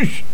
push.wav